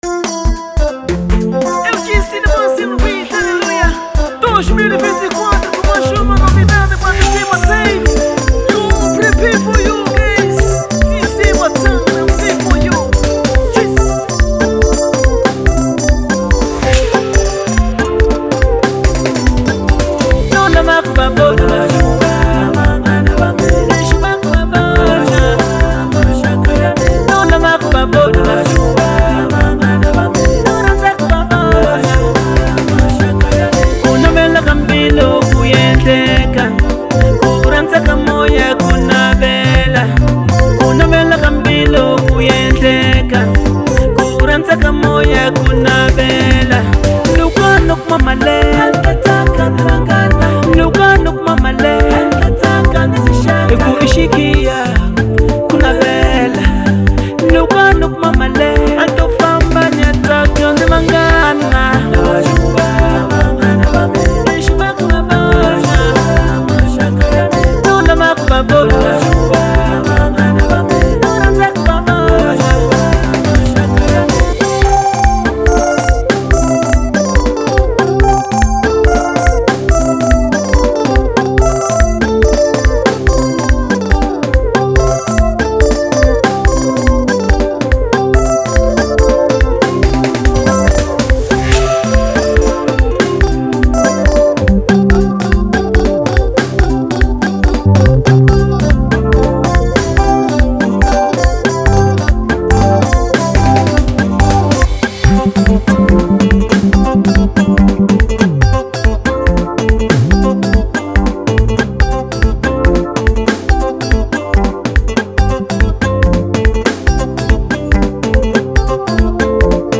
| Afro Congo